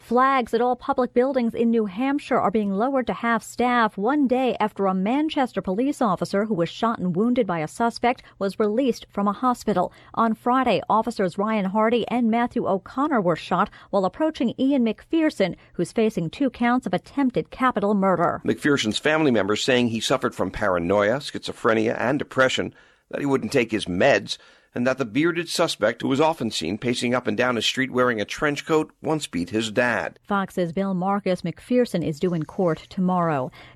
(NEW YORK) FOX NEWS RADIO, 8AM –